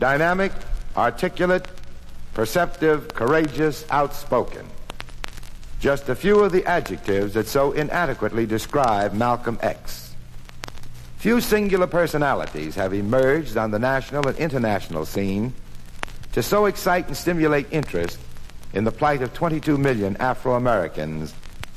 1950年代〜60年代にかけて行われた公民権運動を率いた活動家、マルコム・Xのスピーチ集。
所によりノイズありますが、リスニング用としては問題く、中古盤として標準的なコンディション。